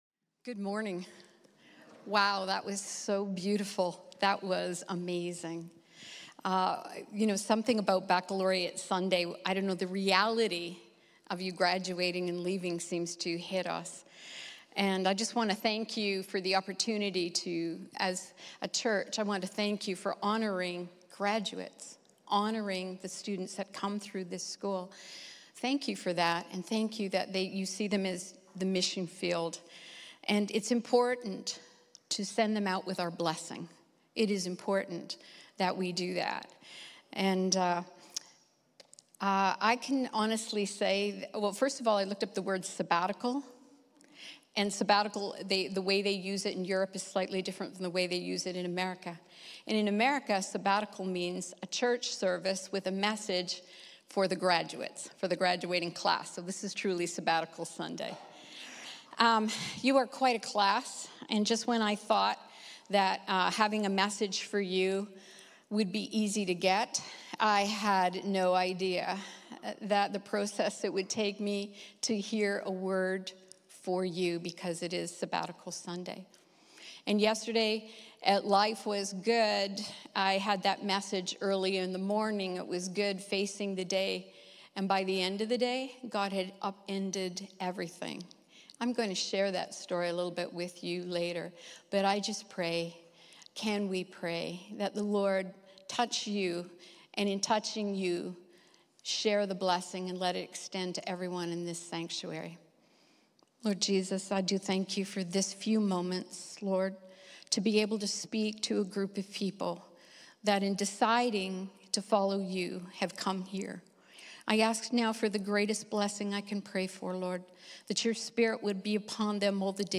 Storm Riders | Times Square Church Sermons